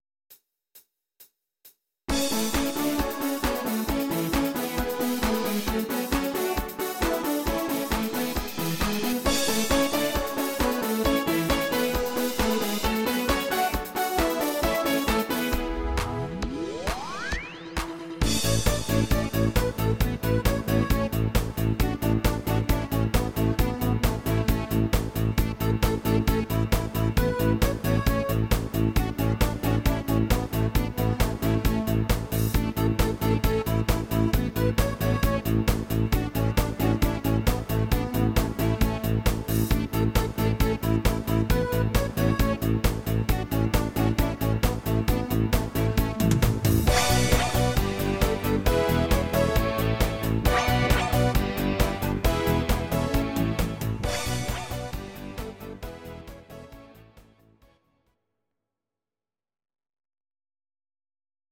Audio Recordings based on Midi-files
German, Duets, 2010s